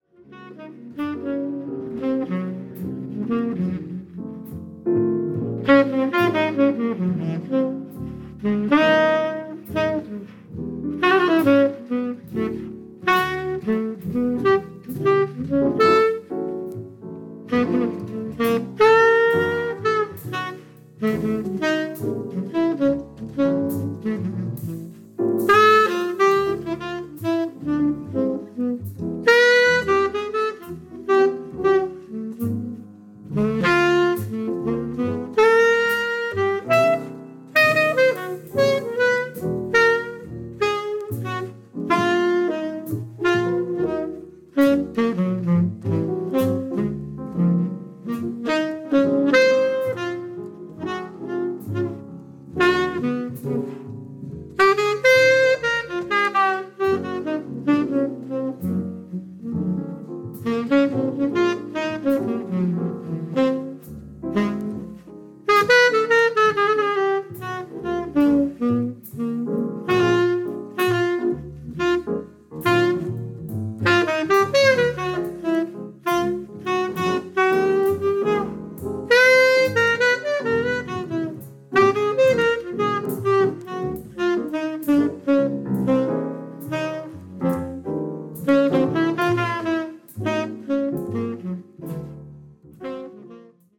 Bass
Piano
Tenor Saxophone